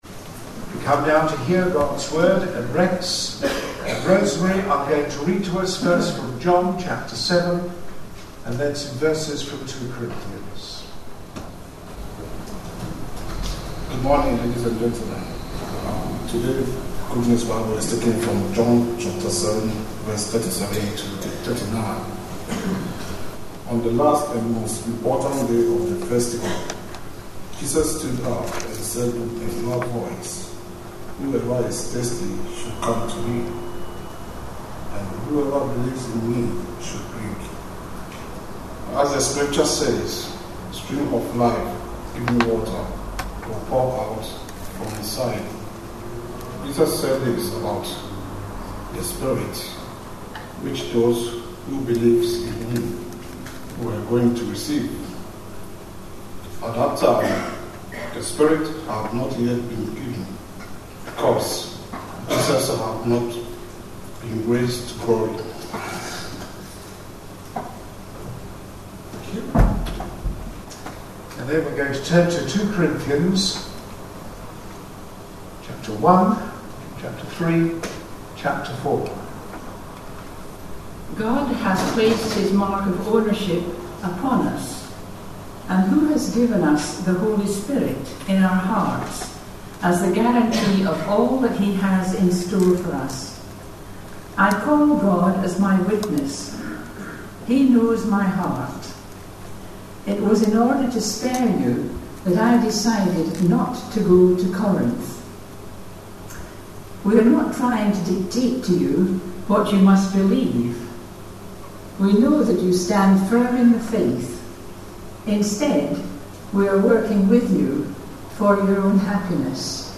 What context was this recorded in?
A sermon preached on 12th June, 2011, as part of our God At Work In Our Lives. series. 2 Corinthians 3 Listen online Details Readings are John 7:37-39 and 2 Corinthians 1:22-24, 3:18, & 4:3-5 (recording slightly unclear during first reading).